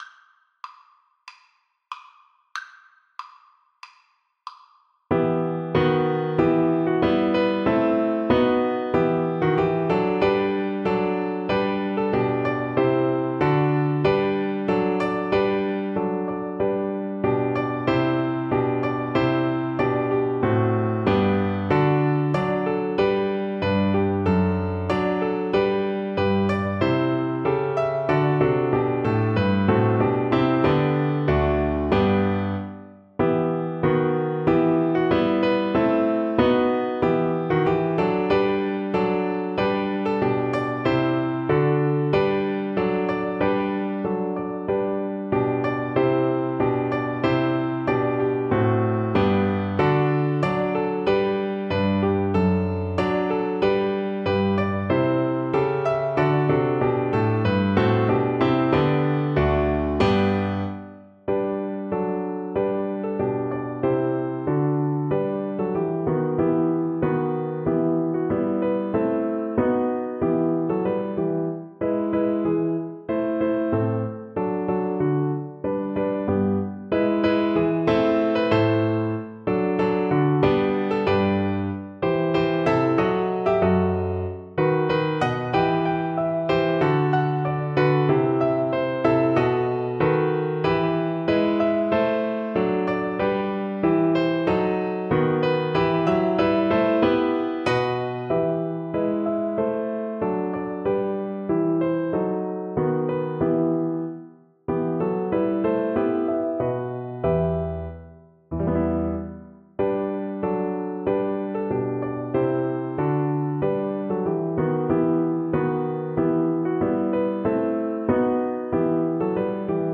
Play (or use space bar on your keyboard) Pause Music Playalong - Piano Accompaniment Playalong Band Accompaniment not yet available transpose reset tempo print settings full screen
Cello
E minor (Sounding Pitch) (View more E minor Music for Cello )
4/4 (View more 4/4 Music)
II: Allegro =94 (View more music marked Allegro)
Classical (View more Classical Cello Music)